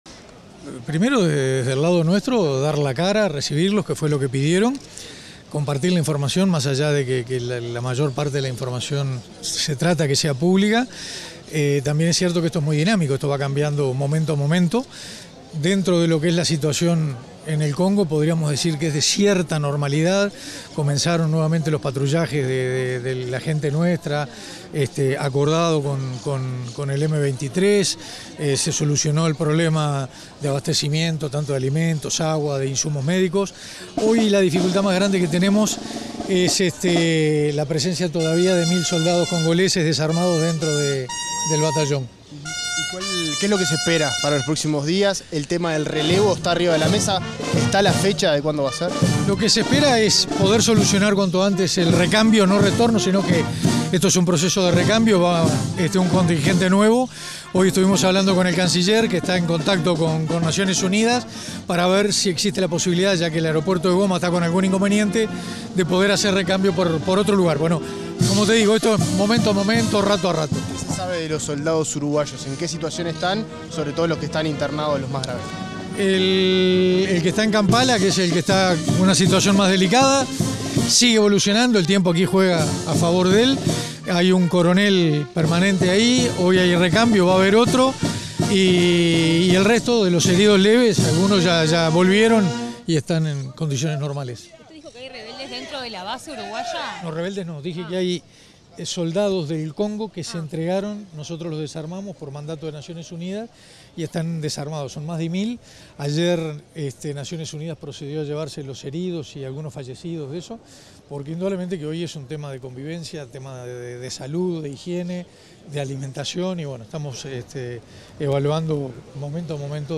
Declaraciones del ministro de Defensa Nacional, Armando Castaingdebat
Declaraciones del ministro de Defensa Nacional, Armando Castaingdebat 03/02/2025 Compartir Facebook X Copiar enlace WhatsApp LinkedIn Tras la asunción del nuevo comandante en jefe de la Fuerza Aérea Uruguaya, este 3 de febrero, el ministro de Defensa Nacional, Armando Castaingdebat, realizó declaraciones a la prensa.